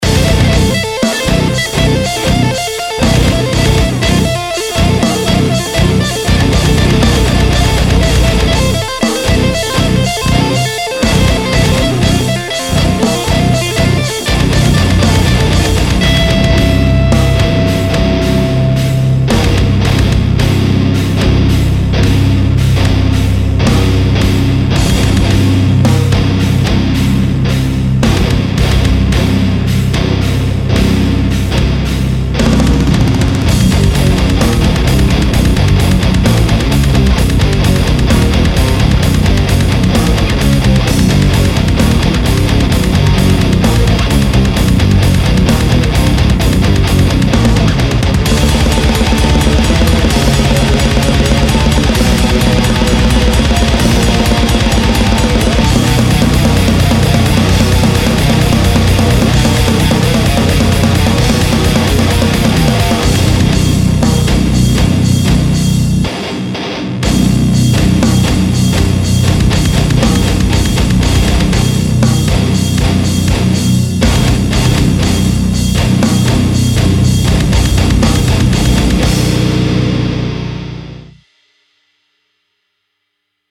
Deathcore